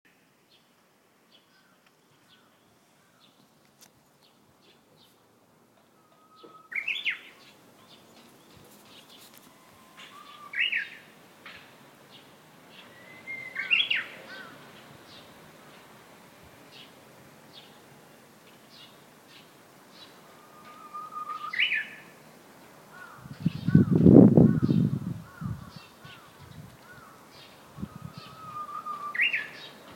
うぐいす
1週間前になってしまうが暖かな朝の陽射しの中、今年初めてうぐいすの声を聴くことができた。